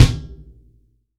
TUBEKICKO3-S.WAV